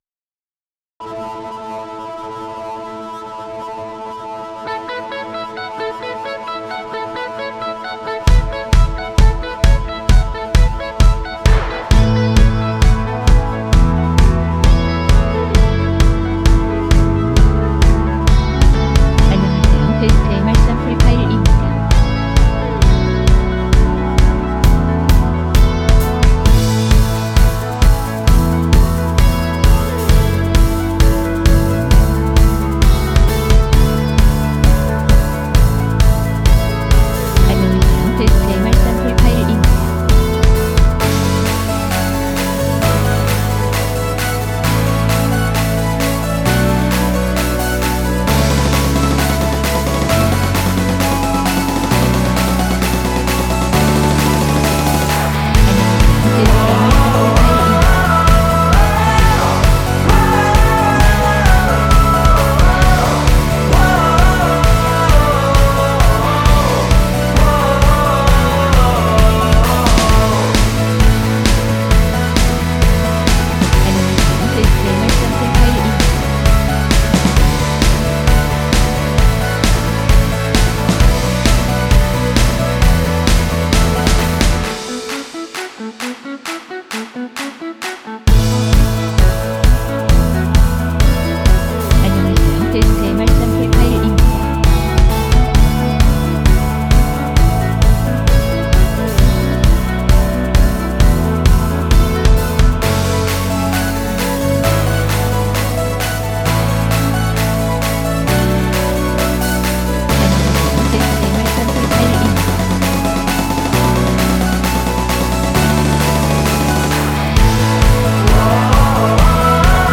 원키 멜로디와 코러스 포함된 MR입니다.(미리듣기 확인)